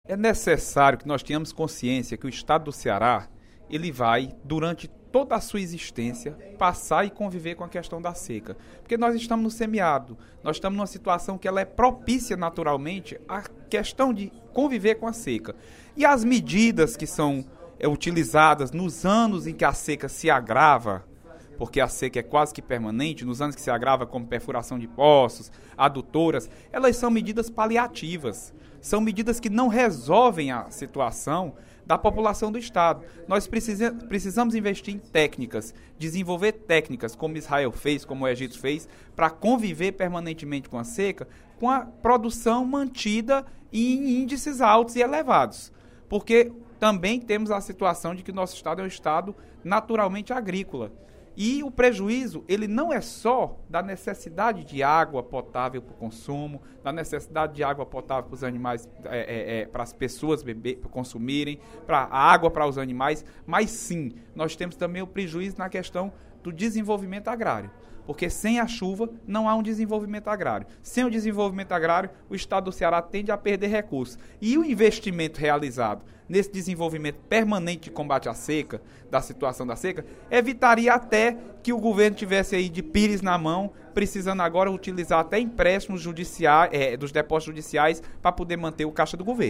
O deputado Leonardo Araújo (PMDB) fez pronunciamento nesta quarta-feira (04/11), no primeiro expediente da sessão plenária, para pedir ações permanentes de combate à seca. Ele considerou que não basta apenas fazer obras emergenciais, mas é necessária a adoção de política pública permanente, e não apenas em períodos de crise hídrica.